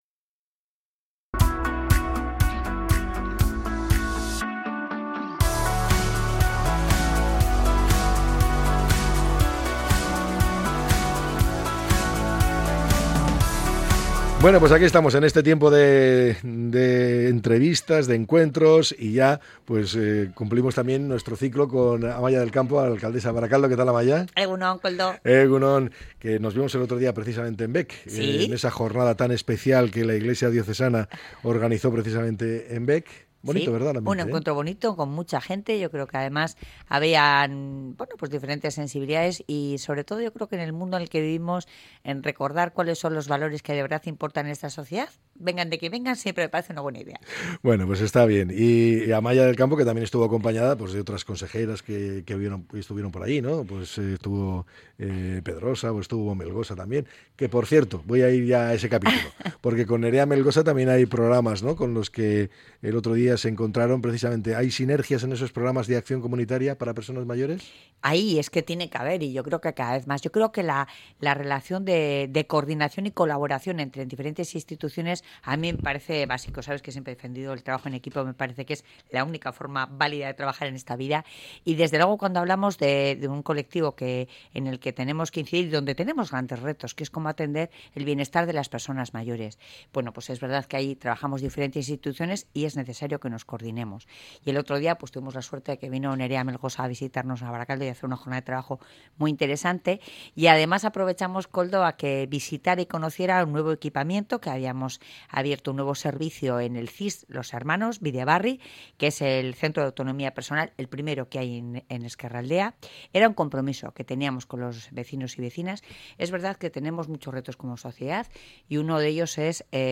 Amaia del Campo ha compartido en 'EgunOn Bizkaia' las principales líneas de acción del Ayuntamiento para construir una ciudad más inclusiva